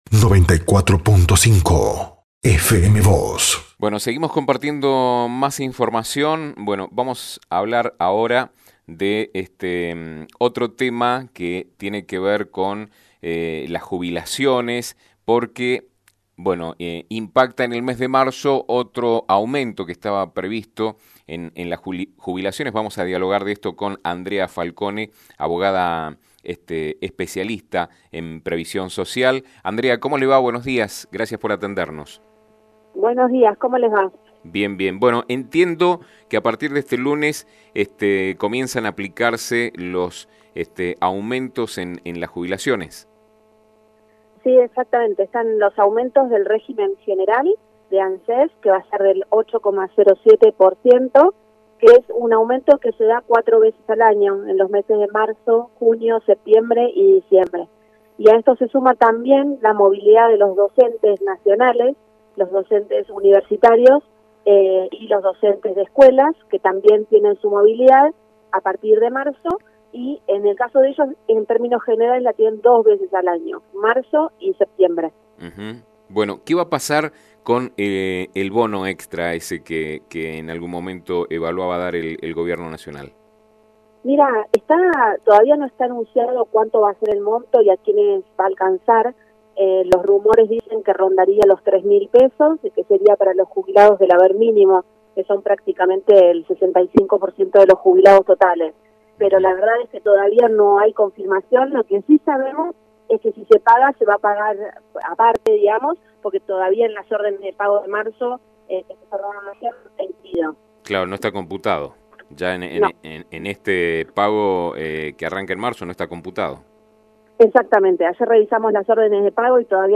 abogada especialista en derecho previsional